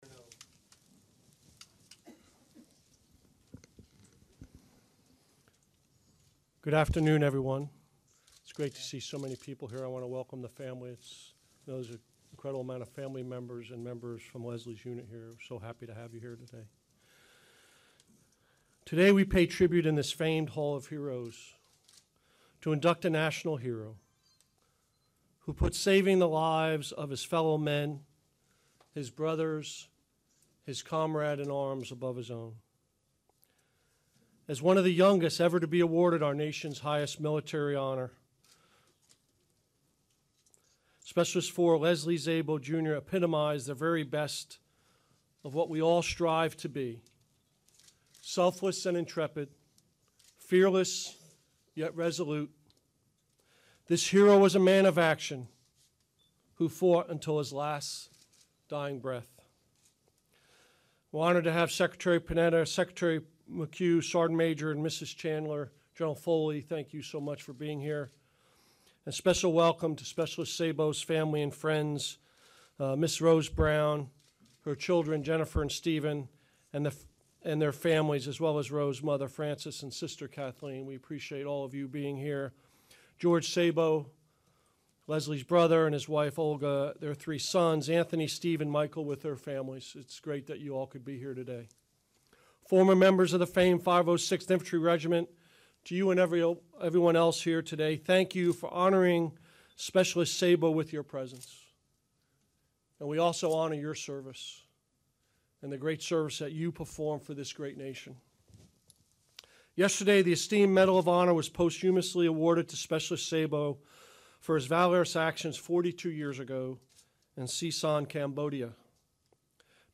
Secretary of Defense Leon Panetta, Secretary of the Army John McHugh and Army Chief of Staff GEN Ray Odierno deliver remarks at the induction ceremony.